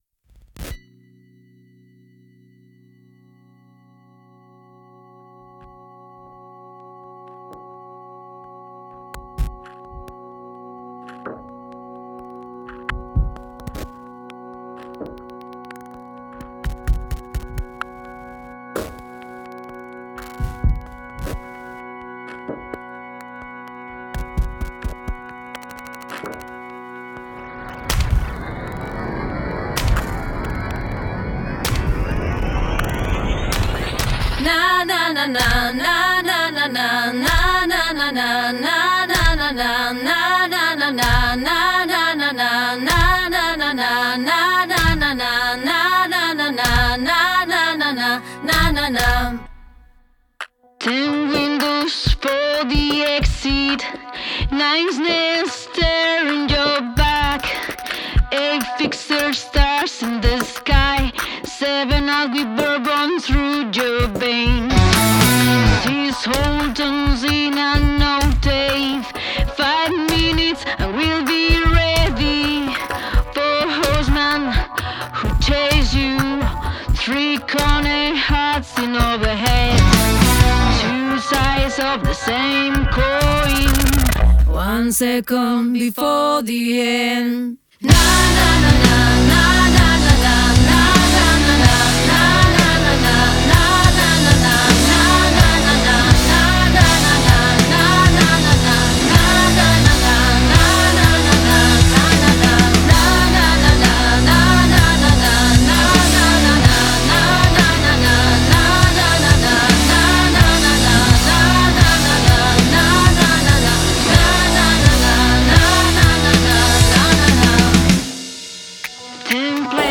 Genre Alternative rock